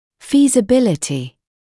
[ˌfiːzə’bɪlɪtɪ][ˌфиːзэ’билити]осуществимость, выполнимость; возможность осуществления